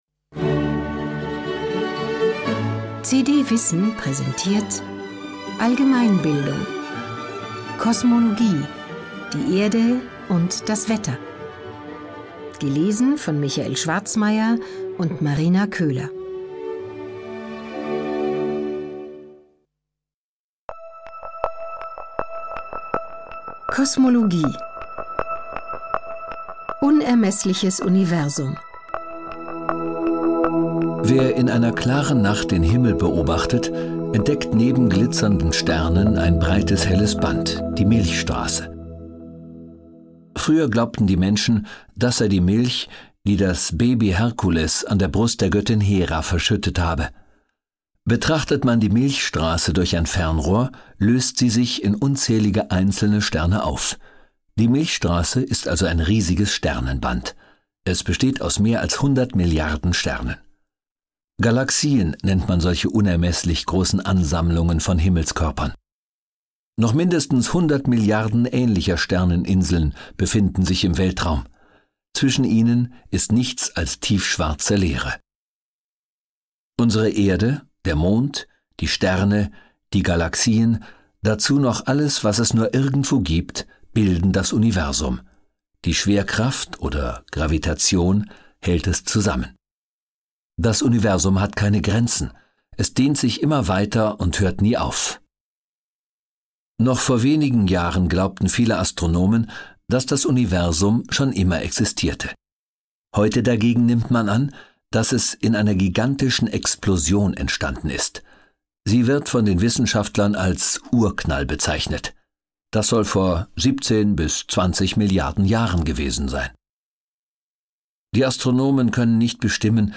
Hörbuch: CD WISSEN - Allgemeinbildung.